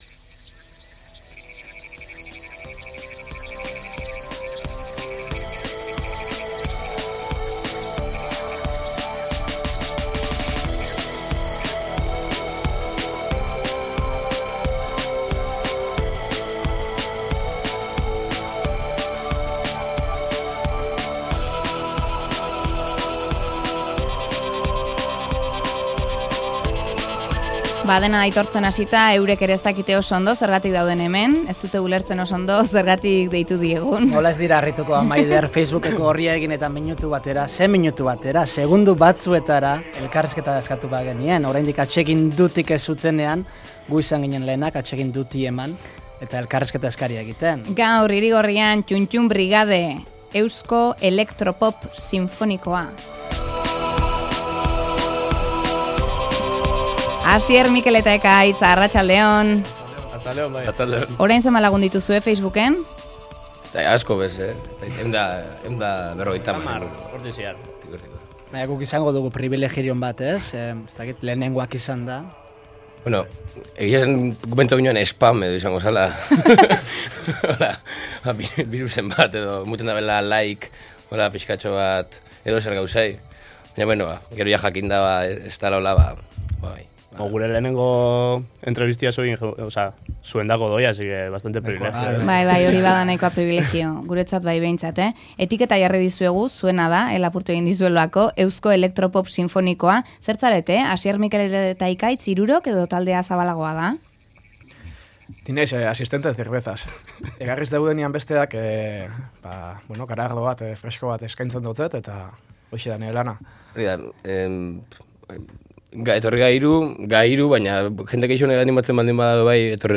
Lehenbiziko elkarrizketa egin diogu Hiri Gorrian Ttun TTun Brigaderi